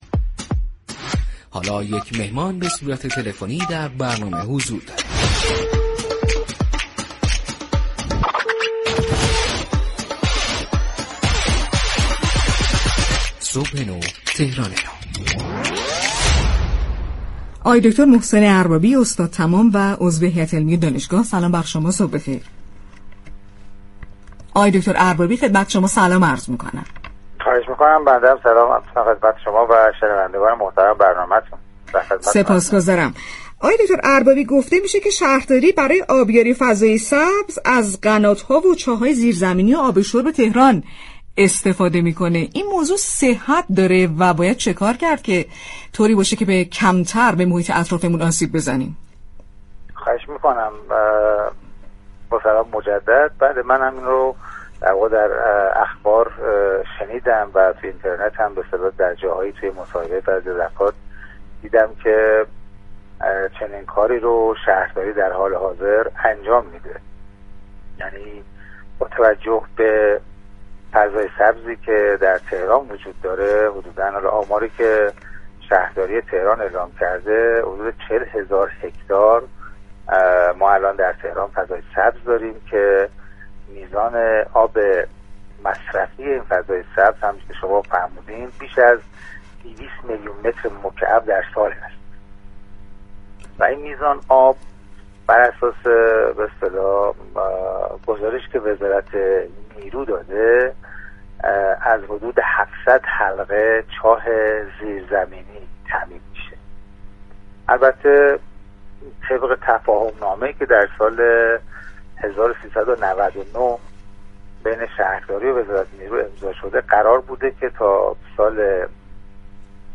مصاحبه رادیویی